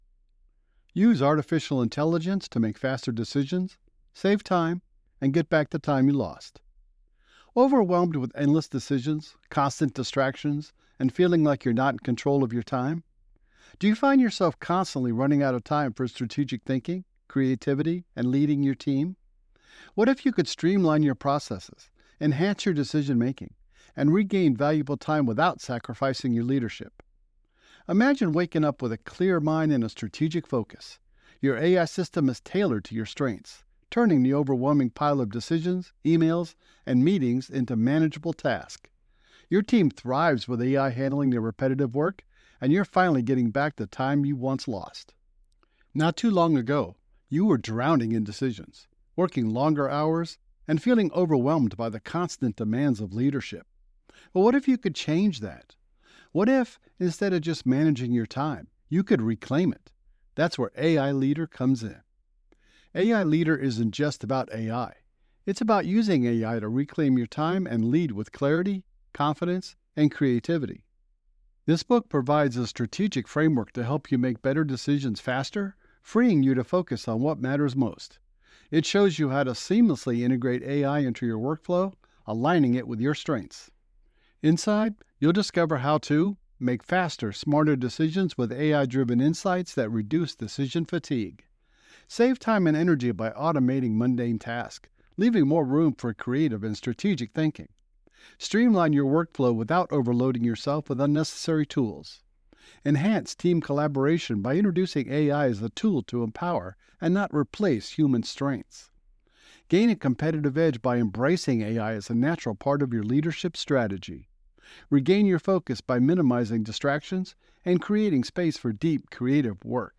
Male
I have a Deep, Friendly, Relatable, Professional voice that can complement almost any project and make it shine!
Audiobooks
Ai Audiobook